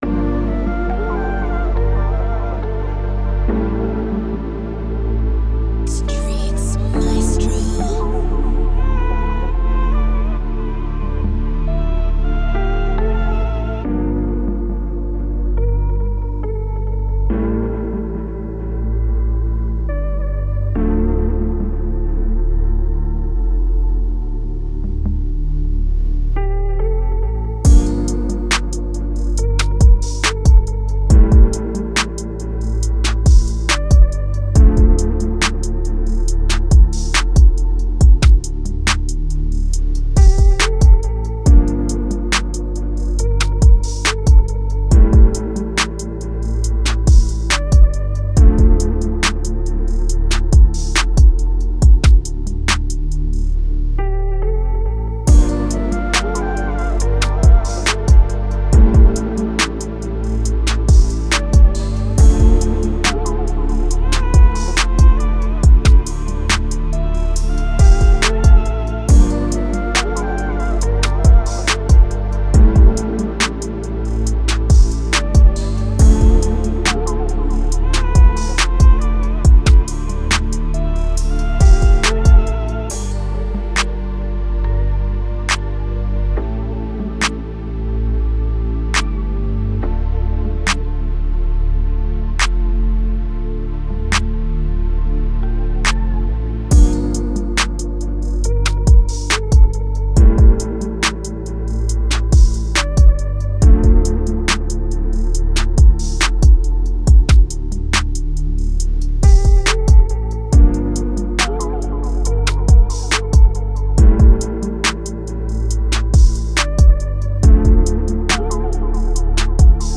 Moods: laid back, mellow, emotional
Genre: R&B
Tempo: 139
laid back, mellow, emotional R&B type beat